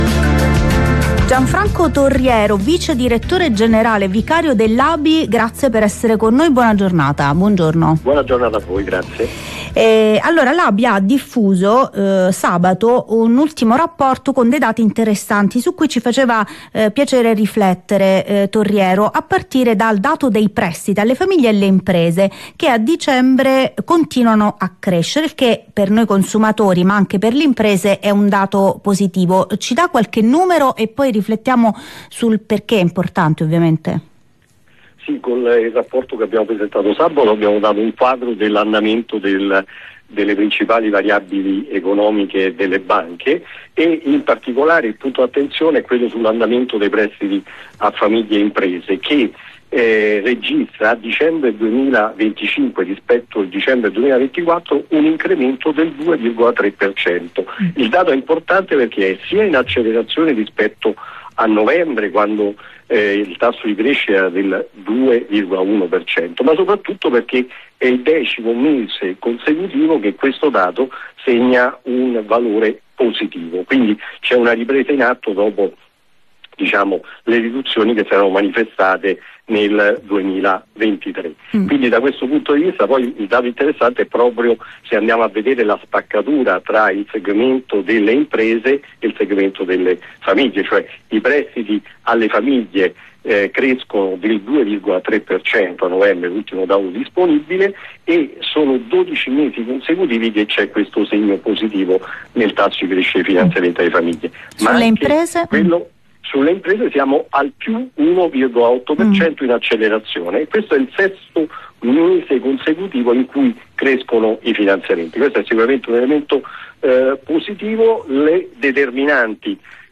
Intervista su Rai RadioUno